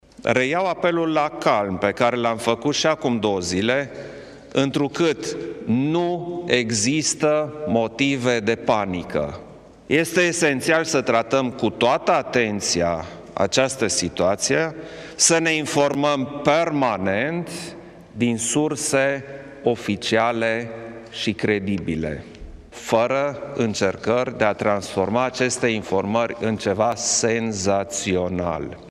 Preşedintele Klaus Iohannis a declarat, astăzi, la finalul Consiliului Suprem de Apărarea a Țării că până în prezent nu există niciun caz confirmat cu coronavirus în România.